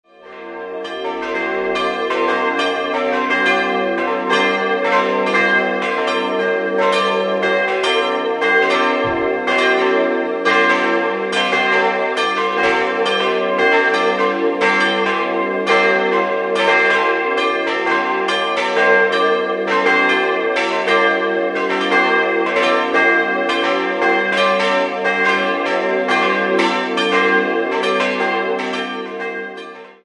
Sie wurde in den Jahren 1770 bis 1775 erbaut und besitzt im Inneren drei schöne barocke Altäre. 4-stimmiges ausgefülltes G-Moll-Geläute: g'-b'-c''-d'' Nähere Daten liegen nicht vor.